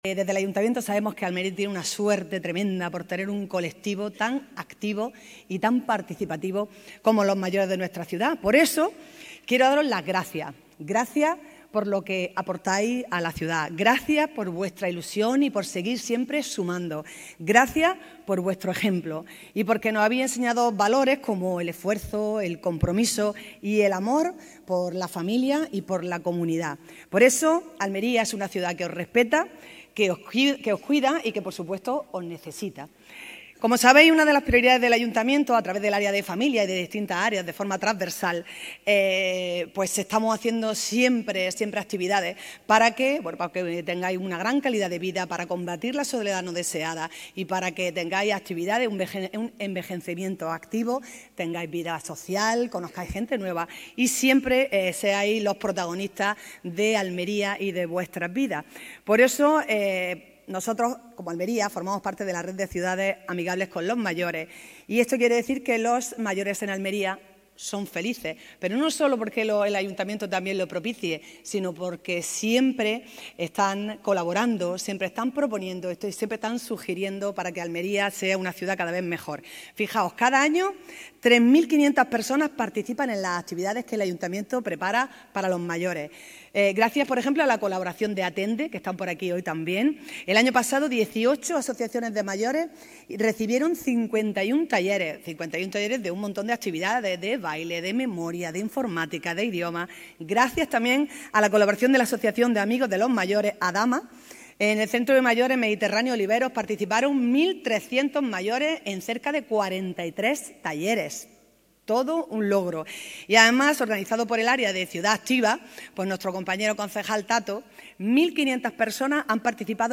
Así lo ha remarcado la alcaldesa de Almería, María del Mar Vázquez, en su intervención en la Gala celebrada esta tarde en el Auditorio Maestro Padilla con la que se conmemora el Día Internacional de las Personas Mayores, que oficialmente es el 1 de octubre, y que en la ciudad, además, incluye una campaña de concienciación en el mobiliario urbano y el congreso sobre Edadismo, que coorganizado con la Fundación FAAM, se llevará a cabo los días 2 y 3 de octubre en el Espacio Alma.
Arropada por un Auditorio repleto de experiencia, la alcaldesa ha manifestado que “ya sabéis que Almería forma parte de la Red de Ciudades Amigables con los Mayores. Y eso quiere decir que Almería es una ciudad en donde se envejece muy bien y en la que los mayores disfrutan de un envejecimiento activo y de un merecido protagonismo”.
ALCALDESA.mp3